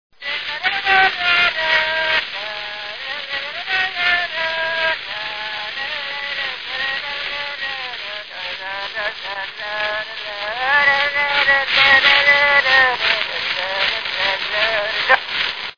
Dallampélda: Hangszeres felvétel
Dunántúl - Somogy vm. - Szenna
lallázva
Műfaj: Csárdás
Stílus: 5. Rákóczi dallamkör és fríg környezete